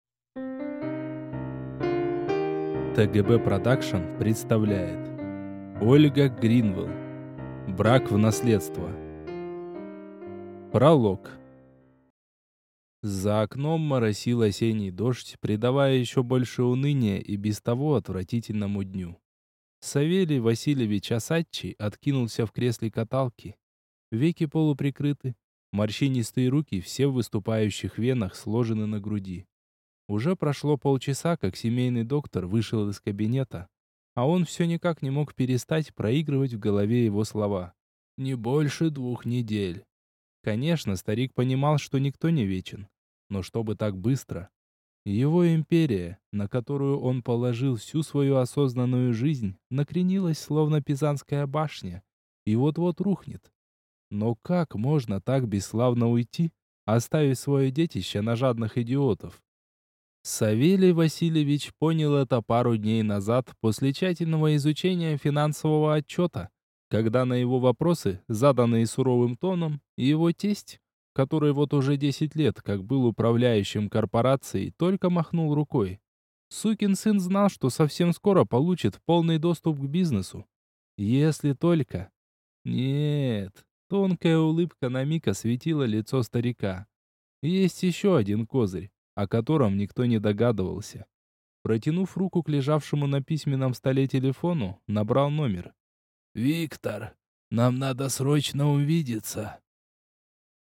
Аудиокнига Брак в наследство | Библиотека аудиокниг